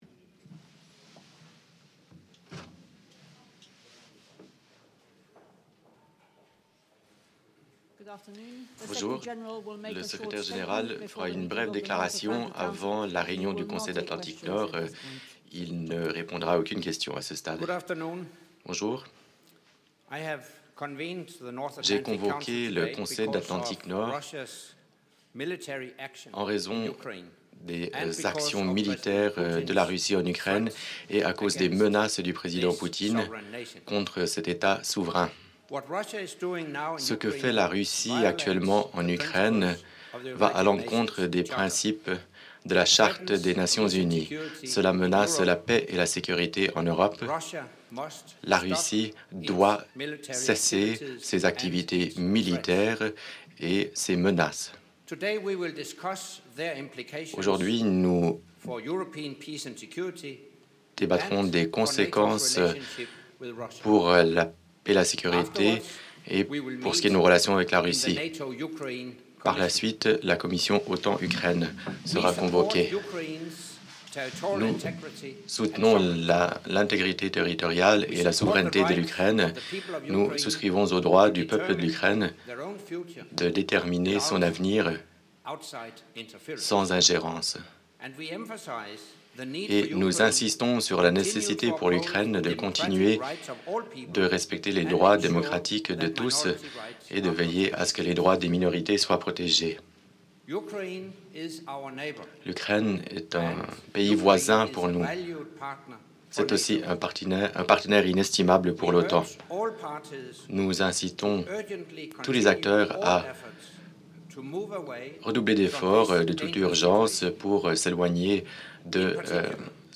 Заявление на пороге Генерального секретаря НАТО Андерса Фога Расмуссена перед заседаниями Североатлантического совета и Комиссии НАТО–Украина